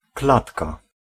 Ääntäminen
Ääntäminen : IPA : [ˈkeɪdʒ] US : IPA : [ˈkeɪdʒ]